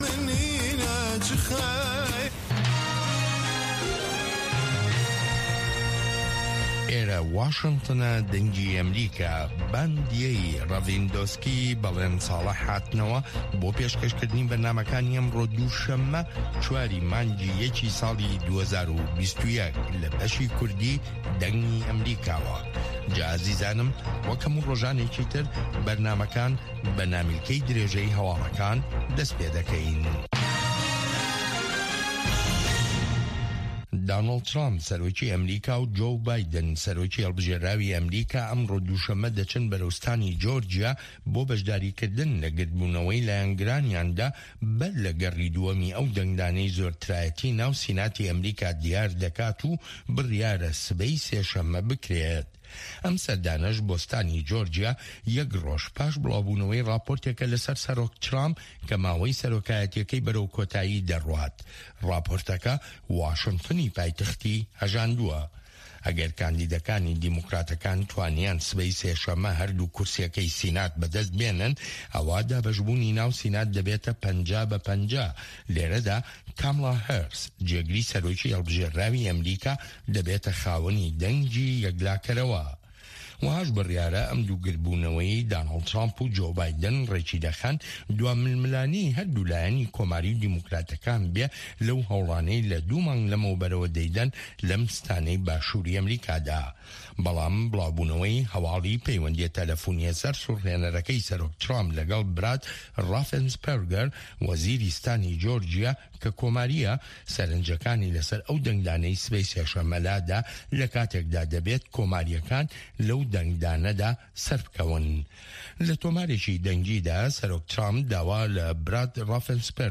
هه‌واڵه‌کان، ڕاپـۆرت، وتووێژ